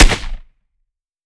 12ga Pump Shotgun - Gunshot A 004.wav